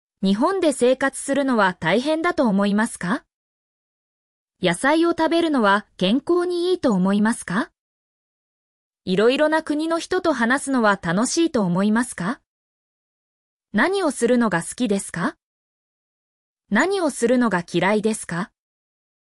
mp3-output-ttsfreedotcom-26_VXX2HI9E.mp3